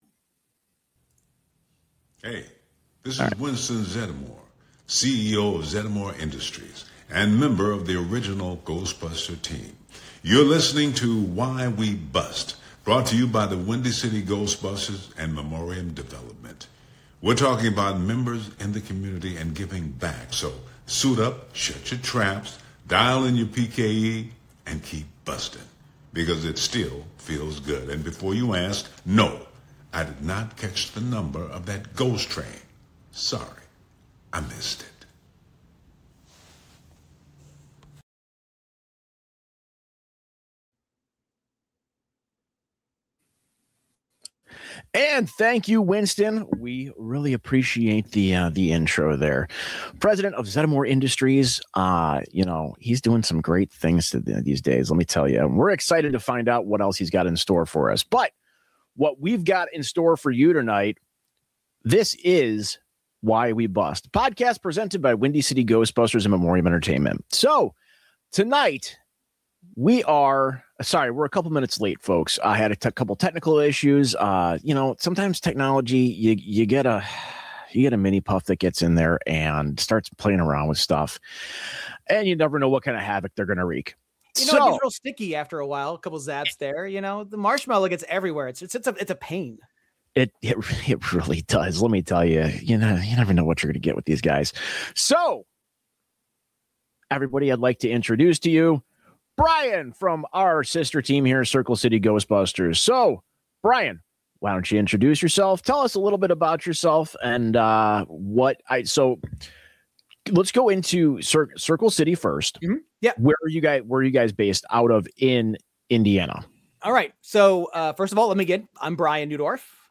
From behind-the-scenes insights to personal reflections on team building and givingback, this interview celebrates the heart of Ghostbusters fandom.